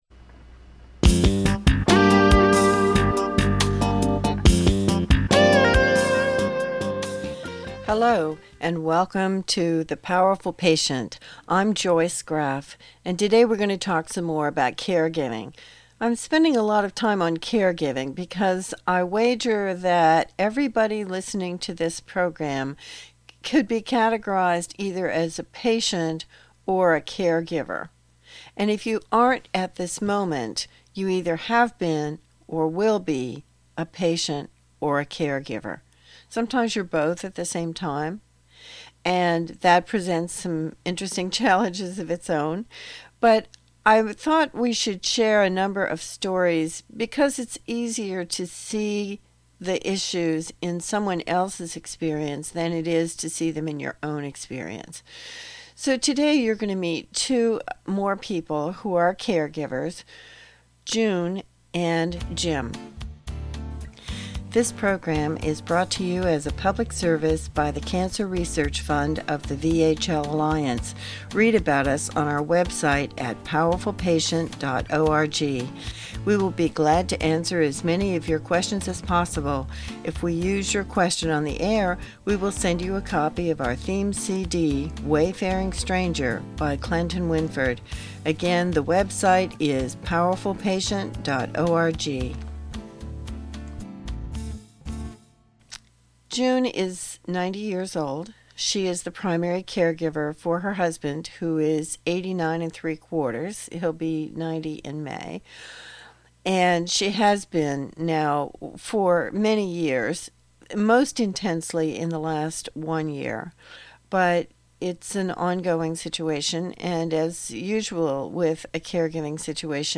As you listen to the two interviews in this program, please think about Compliance Attitude Negotiation